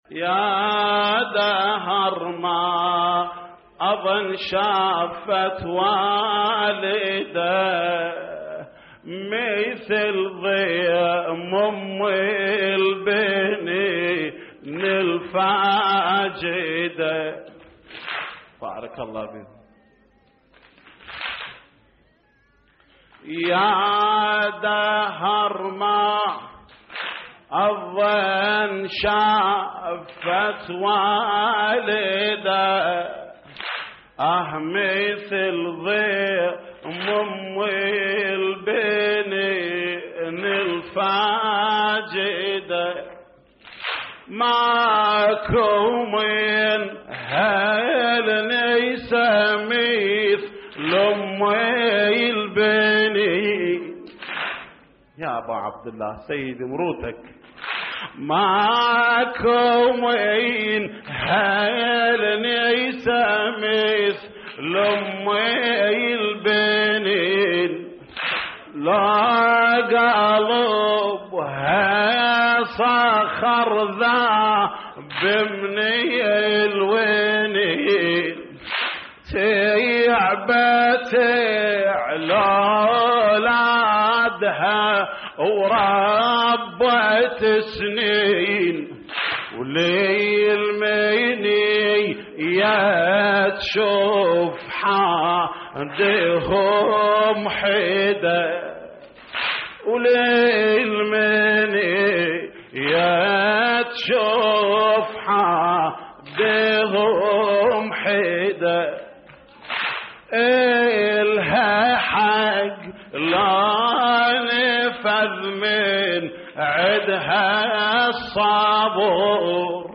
تحميل : يا دهر ما أظن شافت والدة مثل ضيم أم البنين الفاقدة / الرادود جليل الكربلائي / اللطميات الحسينية / موقع يا حسين